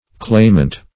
Claimant \Claim"ant\, n. [Cf. OF. clamant, p. pr. of clamer.